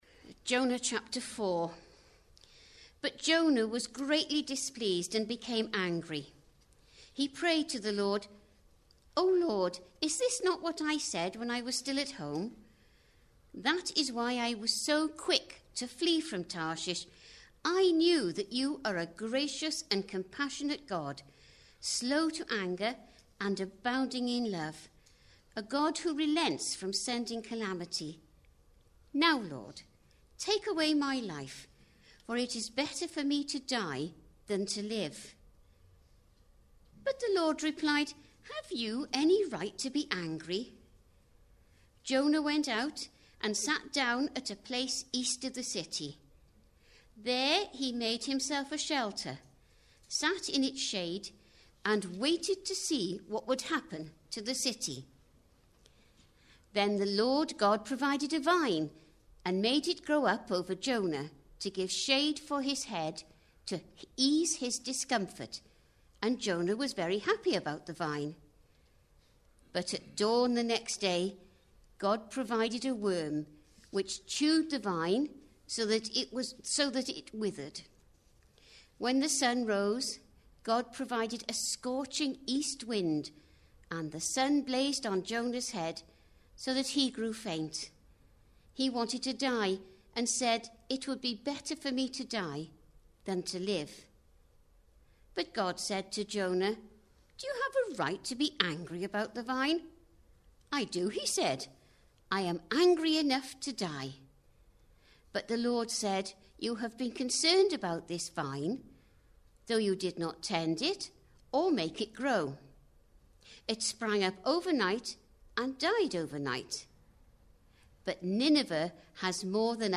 Types of Christ Service Type: Sunday Evening Preacher